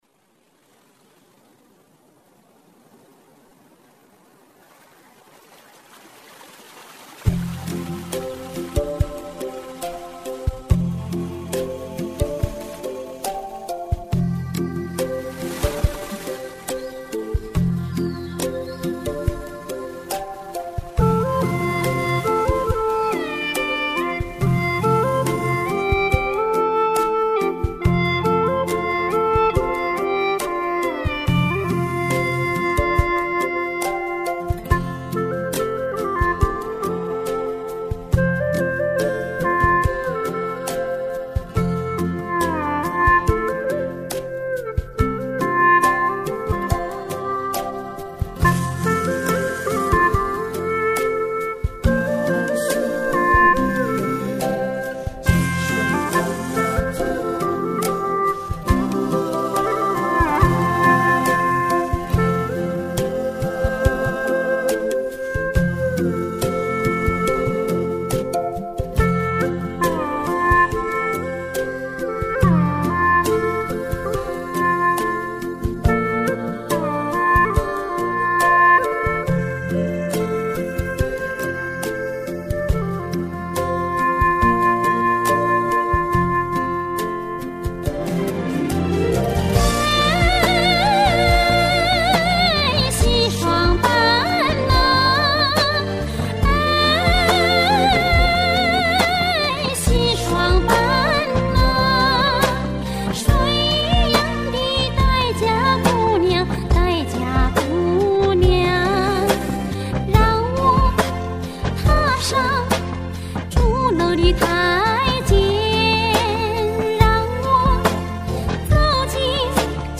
调式 : F 曲类 : 影视
【F调】 我要评论